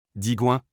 Digoin (French pronunciation: [diɡwɛ̃]
Fr-Digoin.wav.mp3